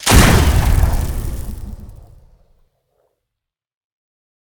pistol1.ogg